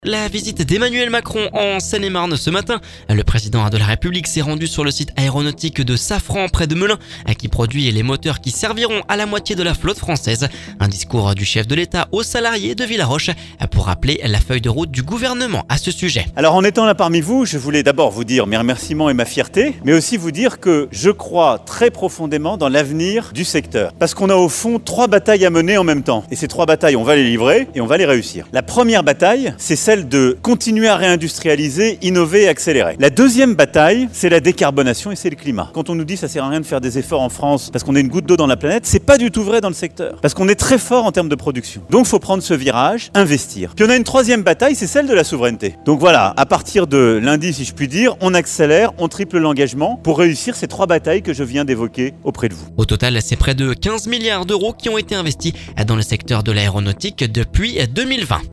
Un discours du chef de l’Etat aux salariés de Villaroche pour rappeler la feuille de route du Gouvernement à ce sujet…